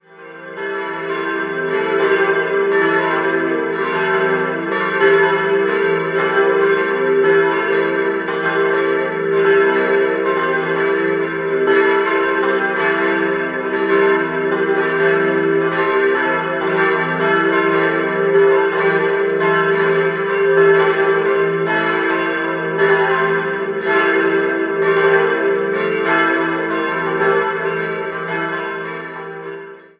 Nach dem Zweiten Weltkrieg entstand ein neuer Glockenturm und 1961/62 wurde das ebenfalls neu errichtete Gotteshaus geweiht. Idealquartett: e'-g'-a'-c'' Die Glocken wurden 1962 von Rudolf Perner in Passau gegossen.